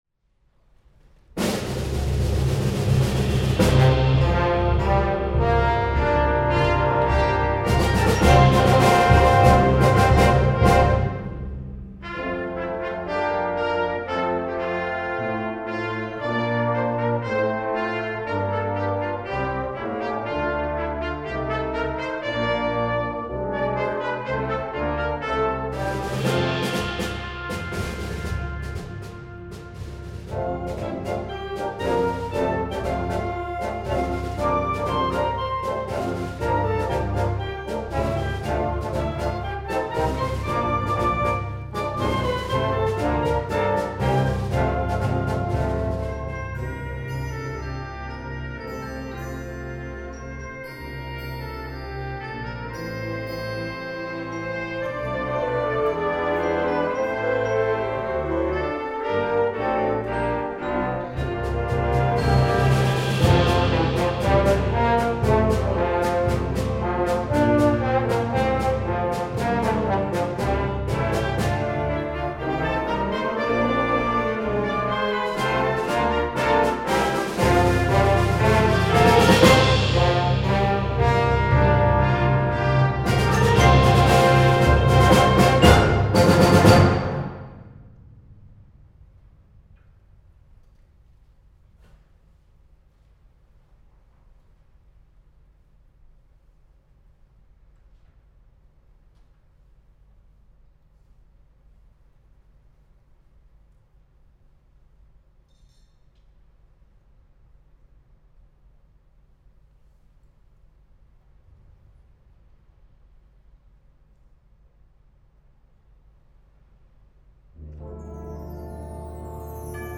KONZERTMUSIKBEWERTUNGEN - JUGENDBLASORCHESTER
>live